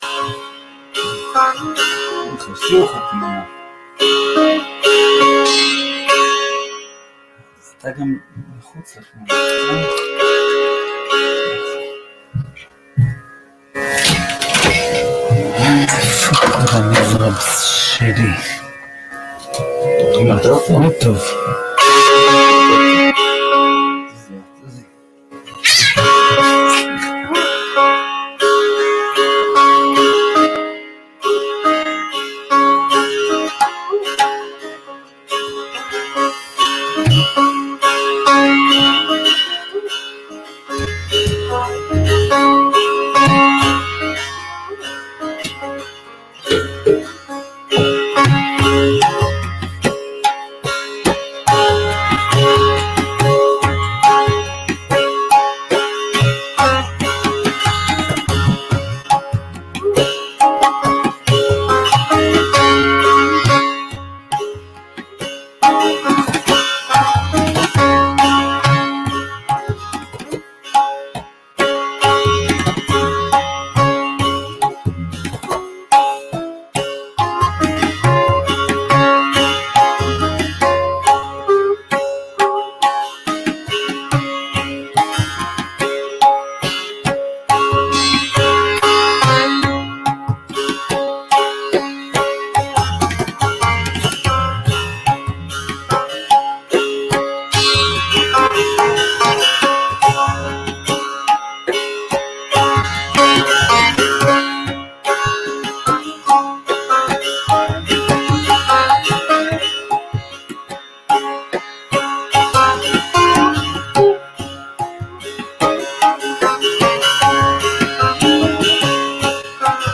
RAAG YAMAN